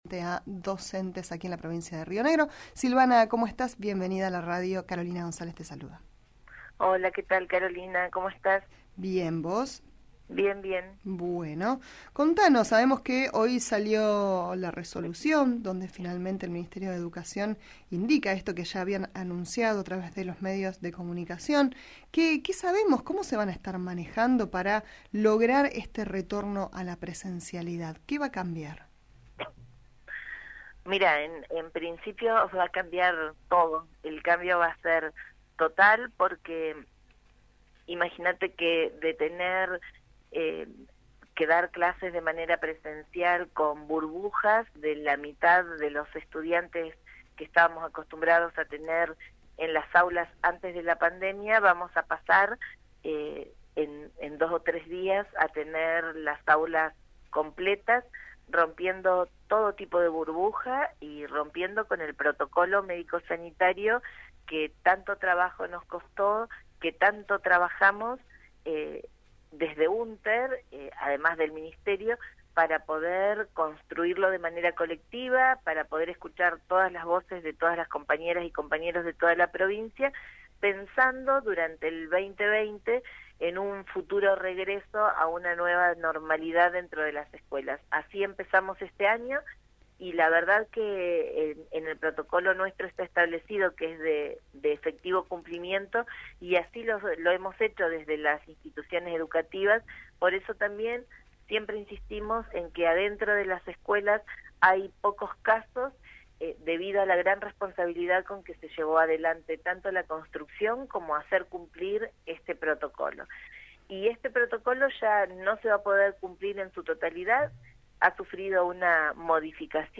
Entrevista de radio